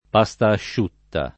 vai all'elenco alfabetico delle voci ingrandisci il carattere 100% rimpicciolisci il carattere stampa invia tramite posta elettronica codividi su Facebook pasta asciutta [ p #S ta ašš 2 tta o pa S t ašš 2 tta ] → pastasciutta